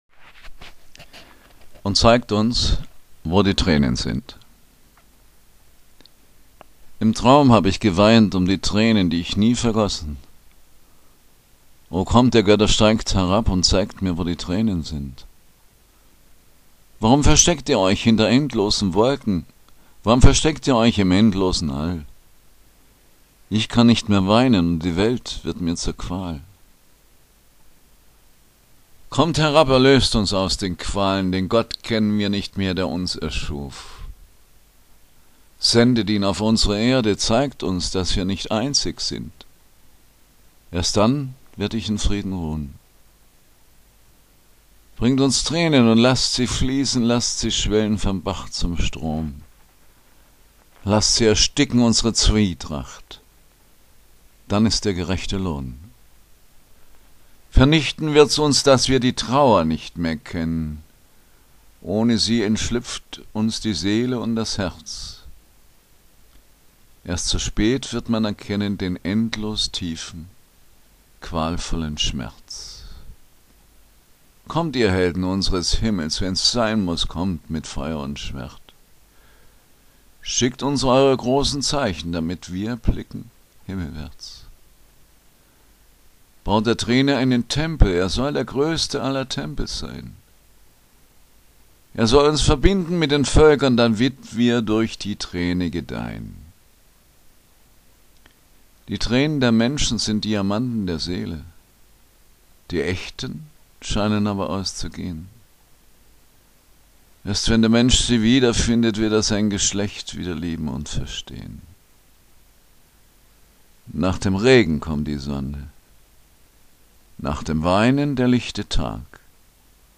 Lyrik zum Anhören